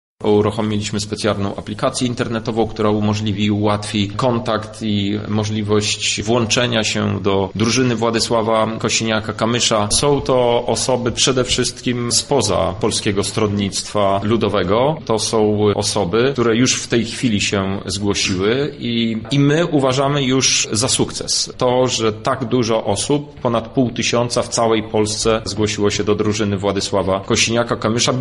Gdy tylko ogłosiliśmy nabór wolontariuszy zaskoczyła nas ilość chętnych – mówi europoseł Hetman.